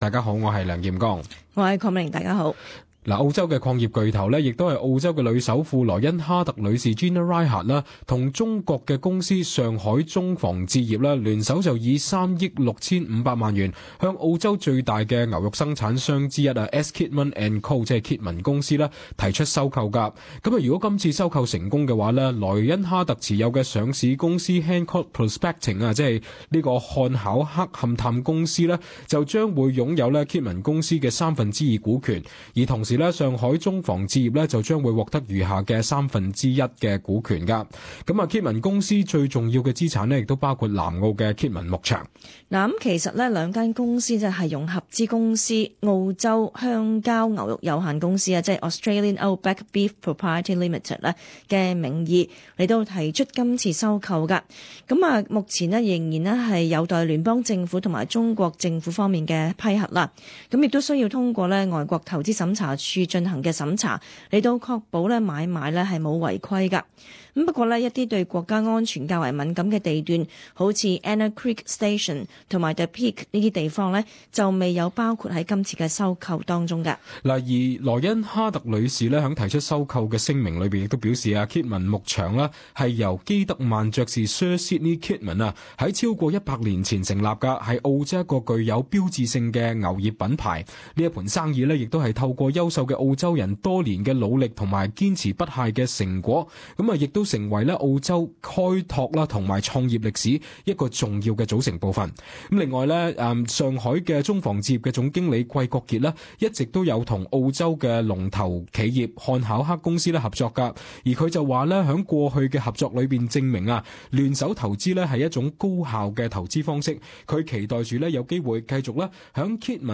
【時事報導】澳洲女首富與中國企業聯手收購澳洲最大牧牛公司